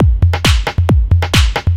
DS 135-BPM A5.wav